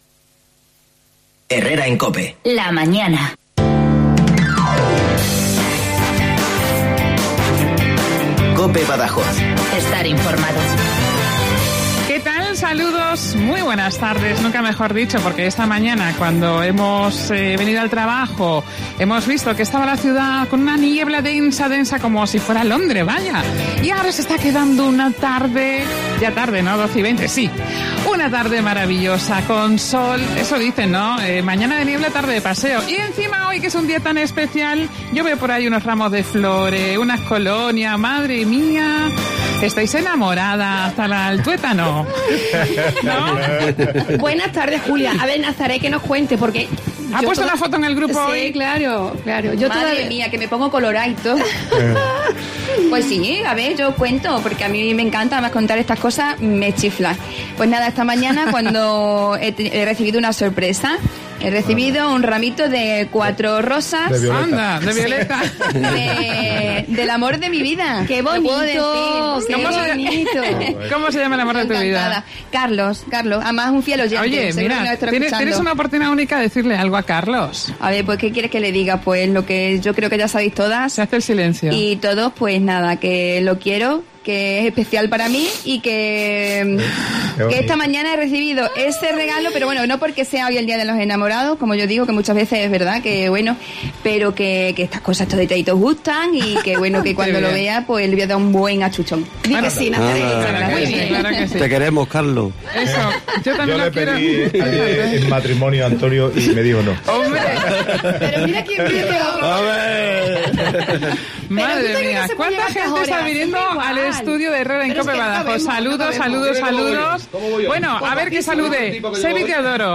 Nuestros comentaristas carnavaleros con el equipo de Herrera en COPE Badajoz